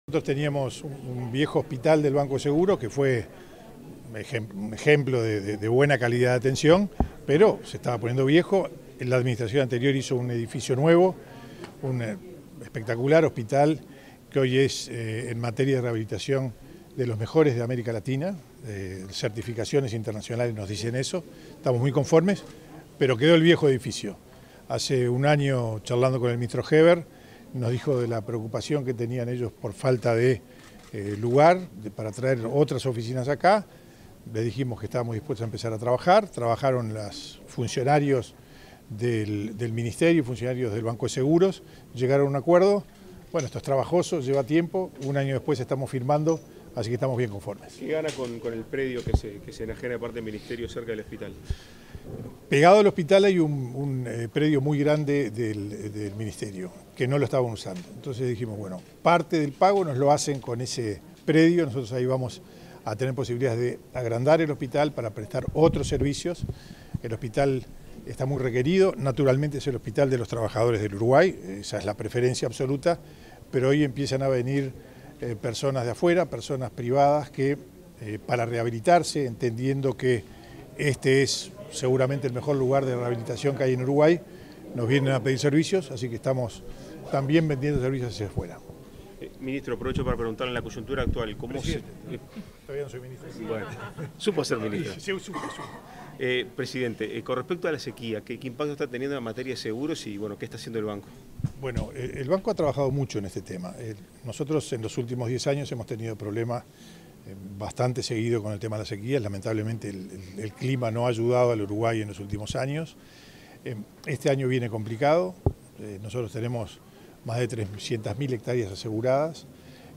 Declaraciones del presidente del Banco de Seguros del Estado (BSE), José Amorín
Declaraciones del presidente del Banco de Seguros del Estado (BSE), José Amorín 17/02/2023 Compartir Facebook X Copiar enlace WhatsApp LinkedIn Tras la firma de un convenio con el Ministerio del Interior, ese 17 de febrero, el presidente del Banco de Seguros del Estado (BSE), José Amorín, realizó declaraciones a la prensa.